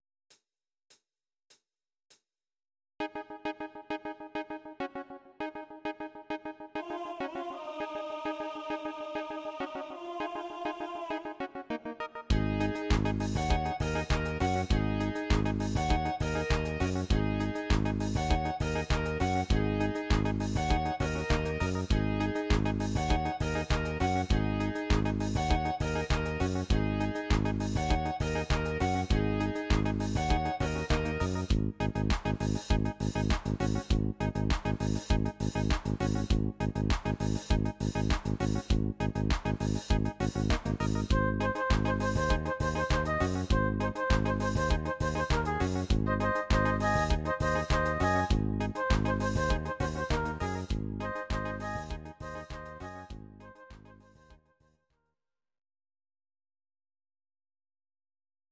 Audio Recordings based on Midi-files
Pop, Ital/French/Span, 1990s